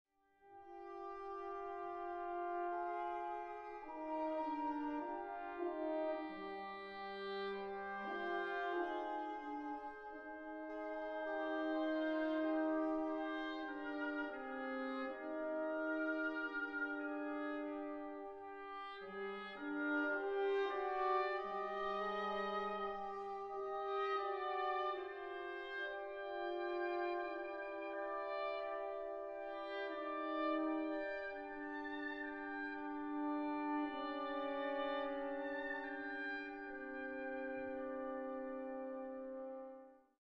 Część wolna wypada znakomicie.